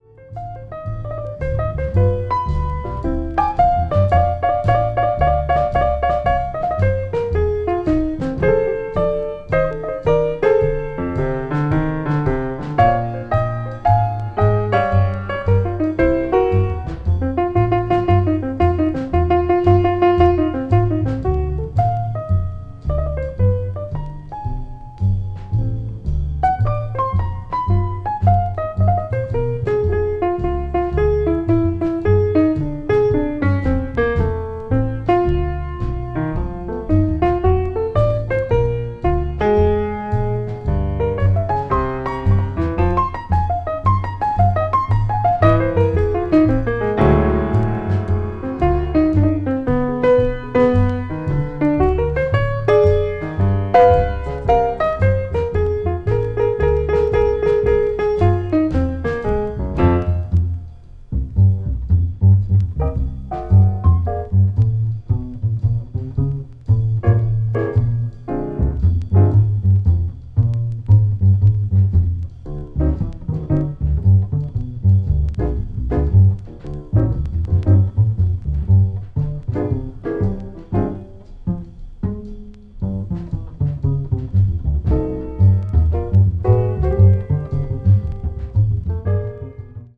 media : EX/EX(some slightly noises.)
bass
drums
bop   modern jazz   piano trio